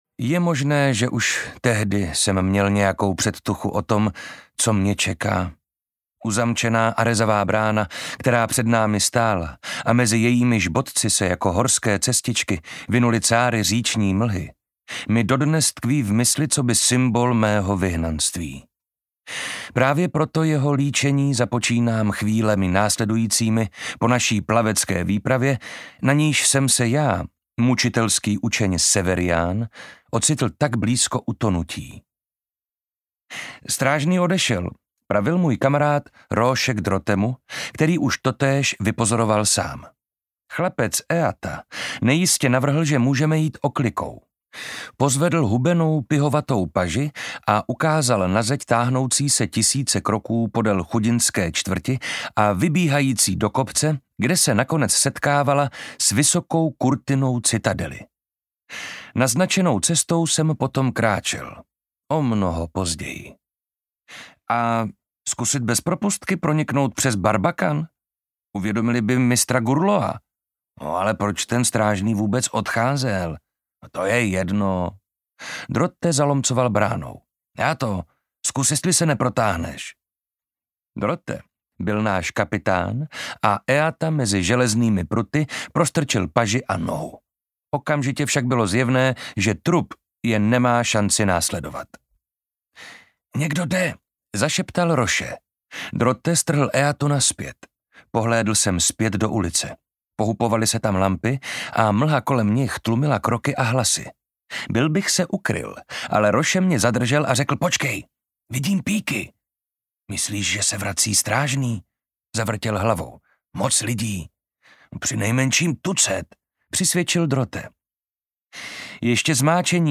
Obálka audioknihy Mučitelův stín